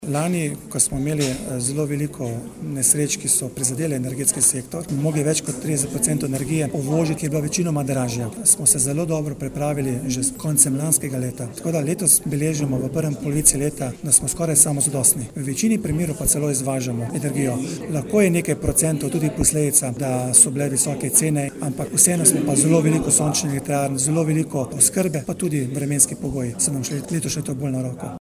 Da so tovrstne naložbe pomembne za oskrbo z energijo v Sloveniji, je poudaril minister za okolje, podnebje in energijo Bojan Kumer: